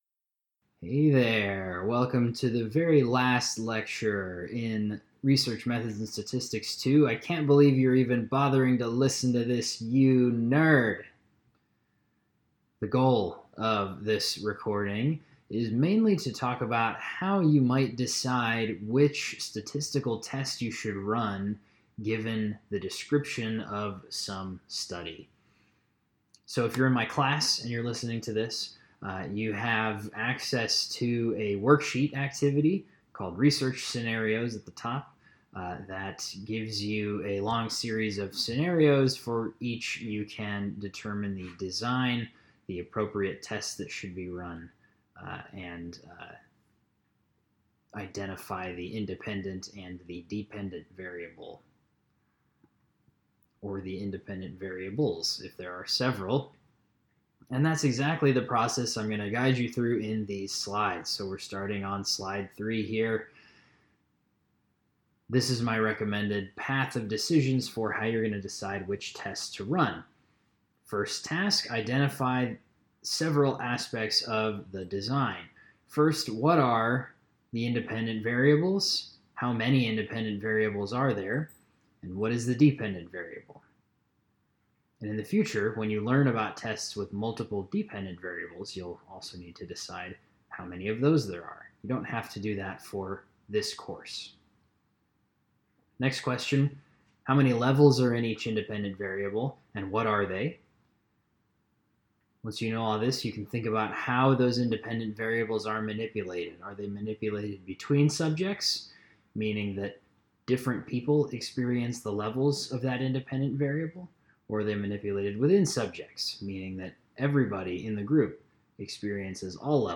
I was rather happy with how this lecture came out. In these slides and this audio, I talk about how to decide which statistical test you might run based on your data. This mostly deals with methods in which you are comparing some number of averages.